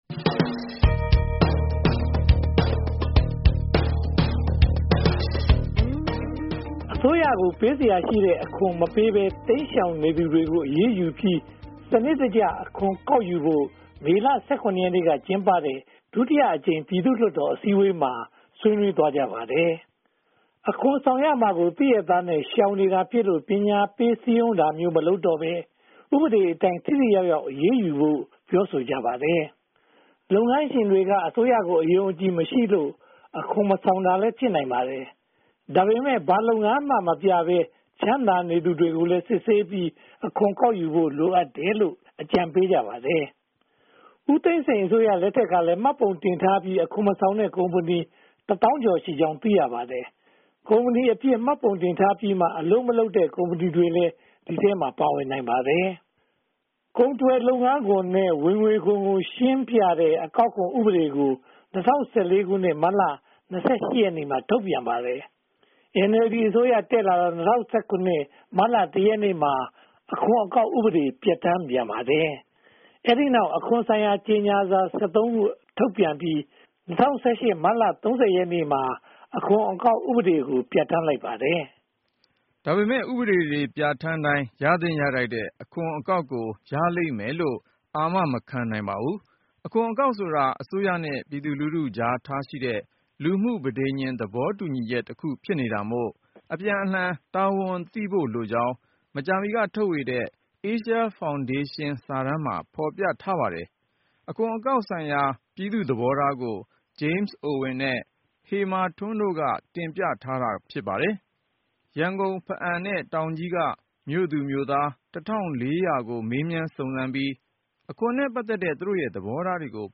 သတင်းသုံးသပ်ချက်